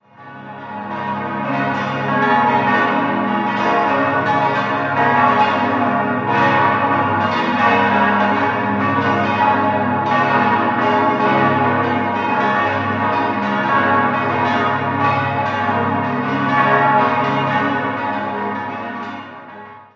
Im Jahr 1924 schließlich konnte die refomierte Gemeinde ein eigenes Gotteshaus, die Berglikirche, in markanter Lage errichten. 7-stimmiges Geläute: as°-b°-des'-f'-as'-b'-c'' Die kleine Glocke wurde 1953, die sechs großen im Jahr 1924 von der Gießerei Rüetschi in Aarau gegossen.